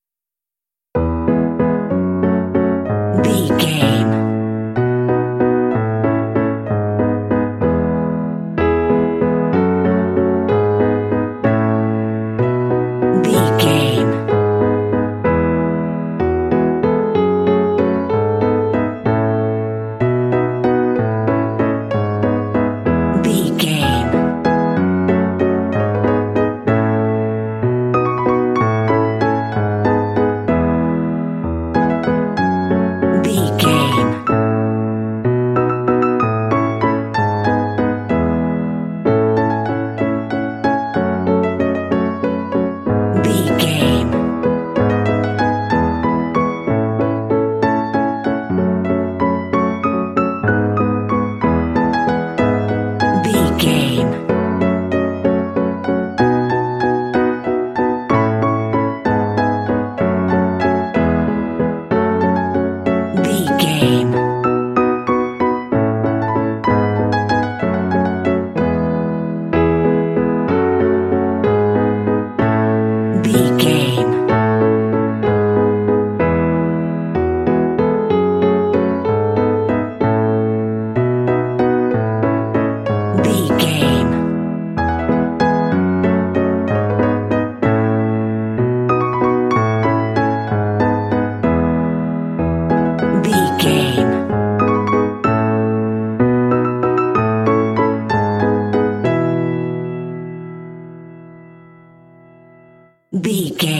Ionian/Major
playful
uplifting
cheerful/happy
piano
contemporary underscore